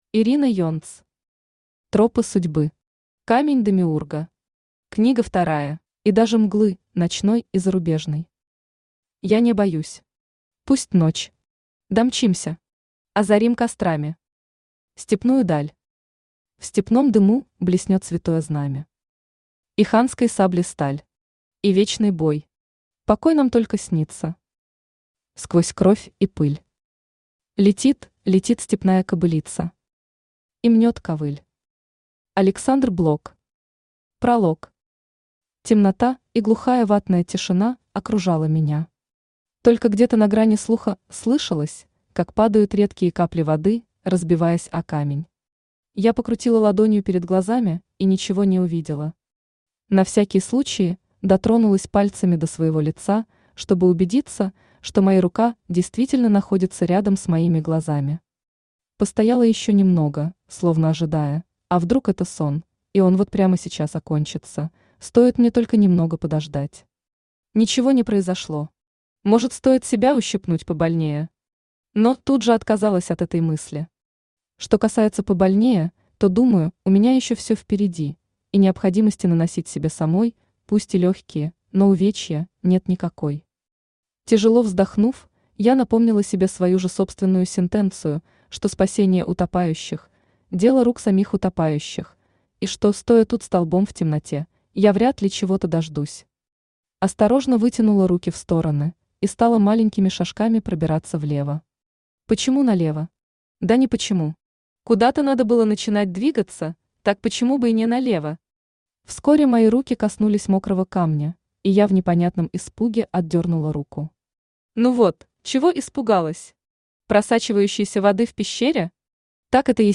Аудиокнига Тропы судьбы. Камень Демиурга. Книга вторая | Библиотека аудиокниг
Книга вторая Автор Ирина Юльевна Енц Читает аудиокнигу Авточтец ЛитРес.